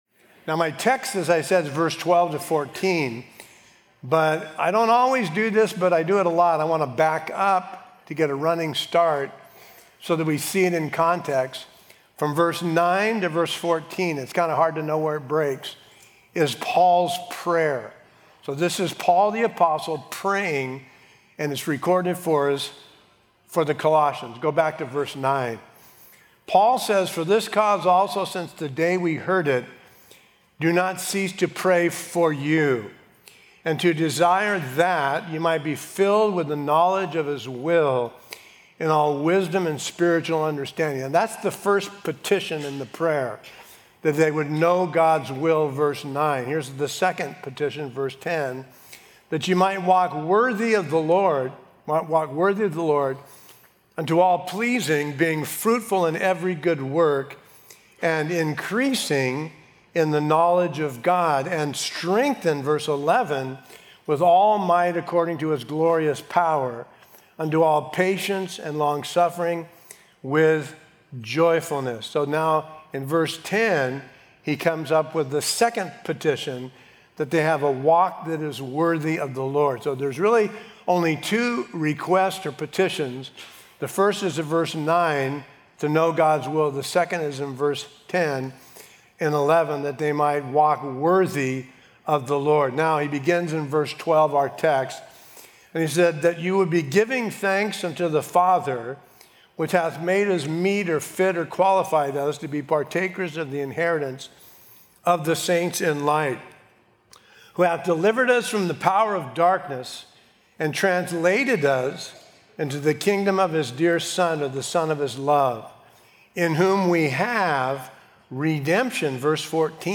Sermon info